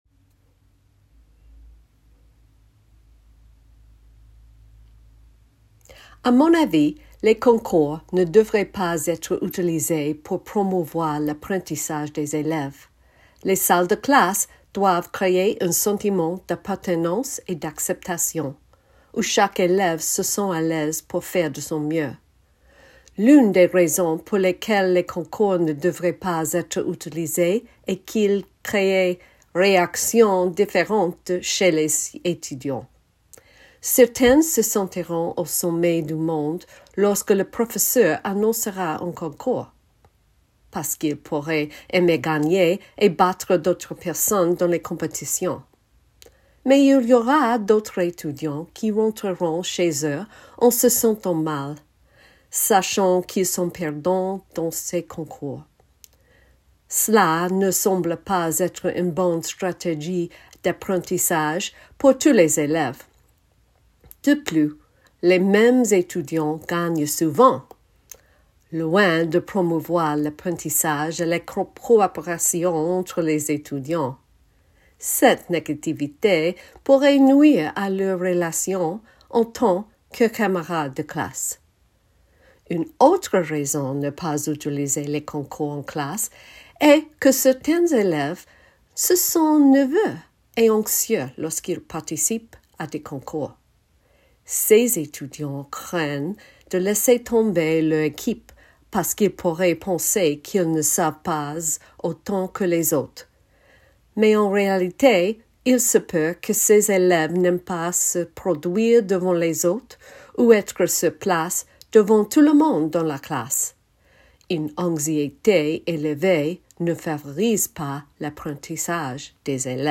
Presentational Speaking: French
[Note: In the transcript below, ellipses indicate that the speaker paused.]
While the sample response demonstrates effective presentational speaking skills, it could contain typical errors that candidates may produce when speaking in French.